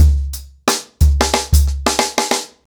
TrackBack-90BPM.19.wav